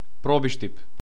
Probištip (Macedonian: Пробиштип [ˈprɔbiʃtip]
Mk-Probishtip.ogg.mp3